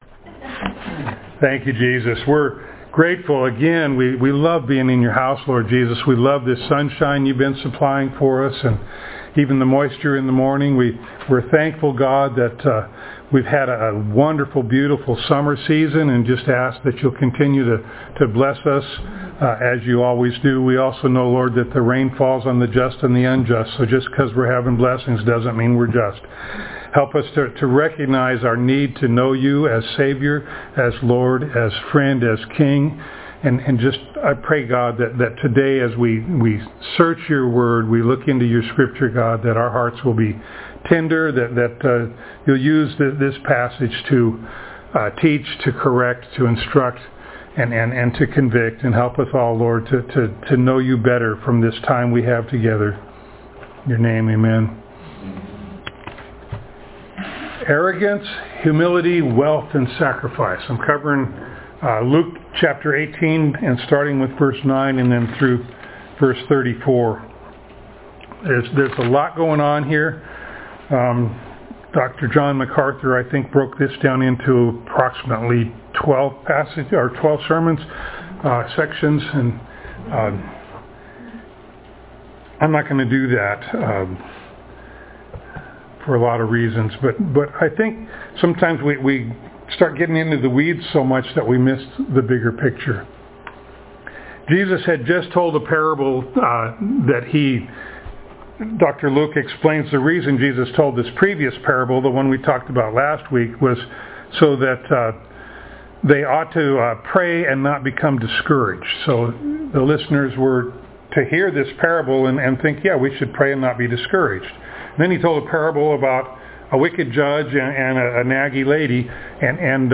Passage: Luke 18:9-34 Service Type: Sunday Morning